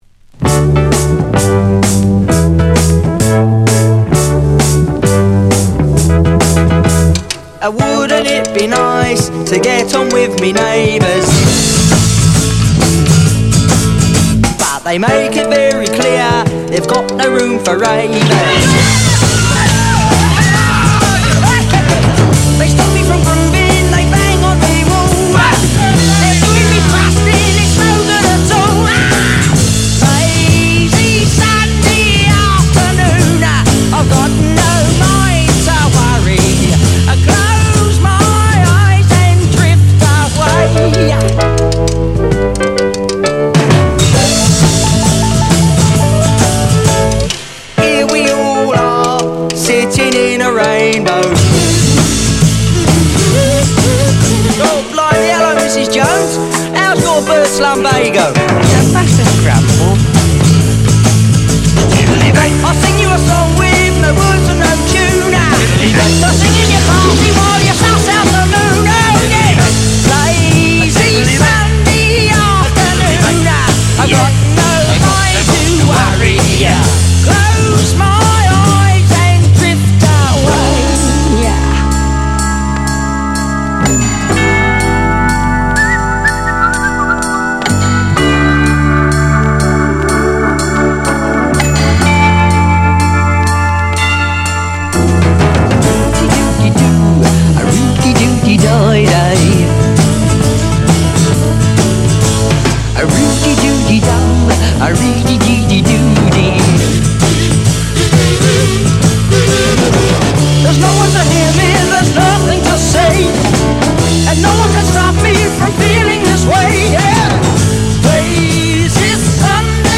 サンデー・ロック DJ 必携盤！
• 特記事項: MONO
▲DISC: 小キズ散見。ところどころノイズ有（➡要試聴）